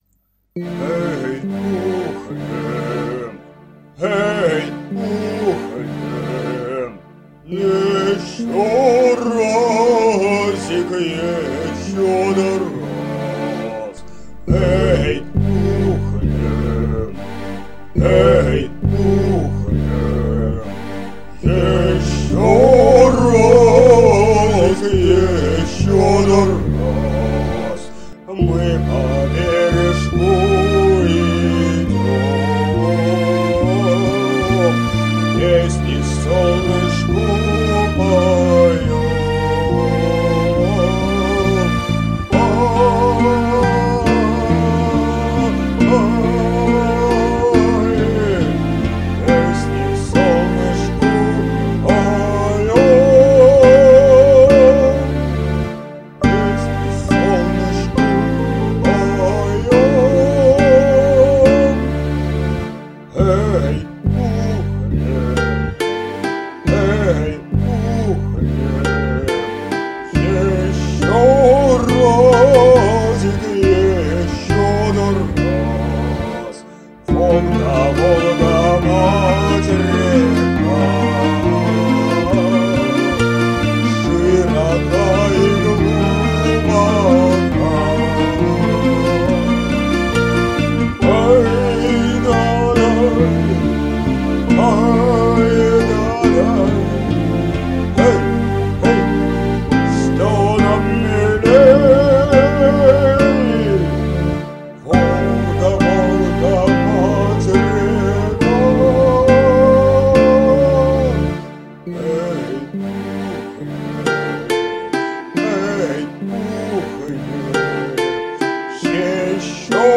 по моему классно вписалась сирена в конце твоей записи!